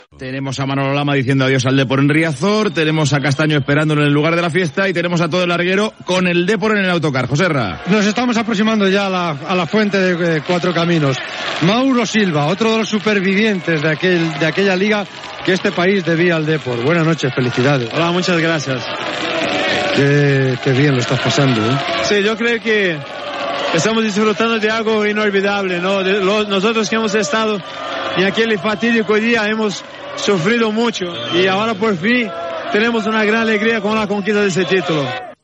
Transmissió de la celebració del títol de lliga de primera divsió de futbol masculí per part del Deportivo de La Coruña, a la temporada 1999-2000.
Esportiu